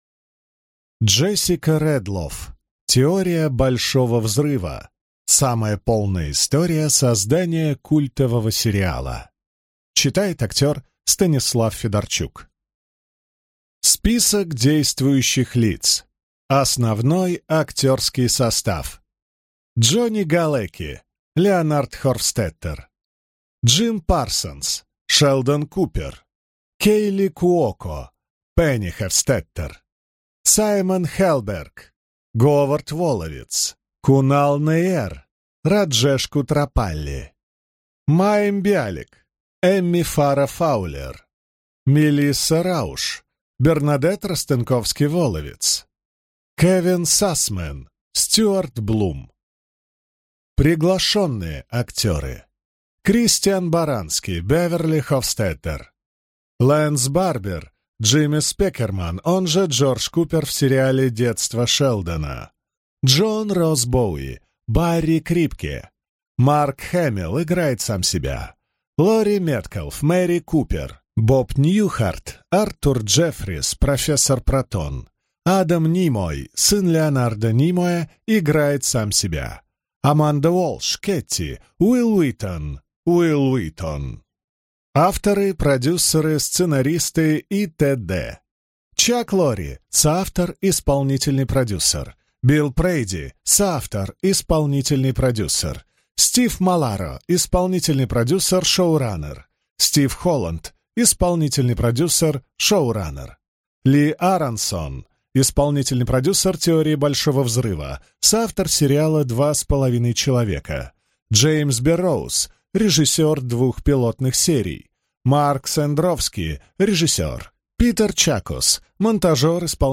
Аудиокнига Теория Большого взрыва. Самая полная история создания культового сериала | Библиотека аудиокниг